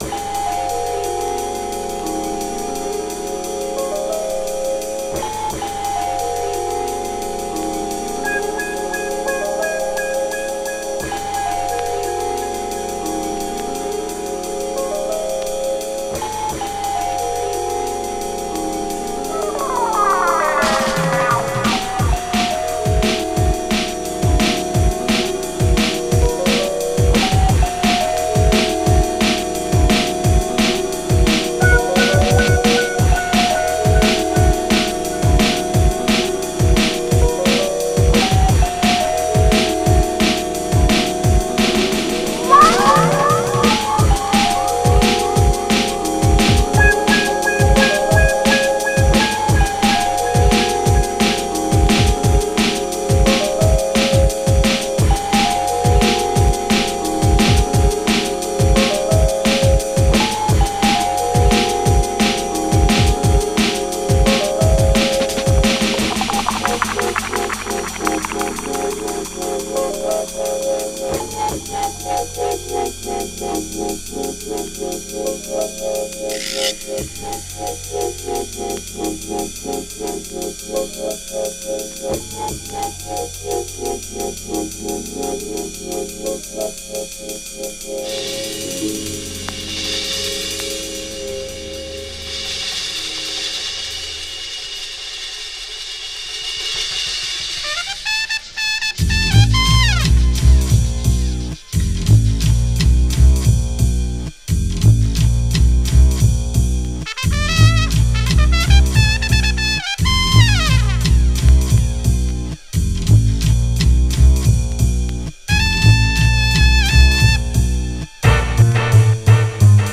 > CROSSOVER/ACID JAZZ/CLUB JAZZ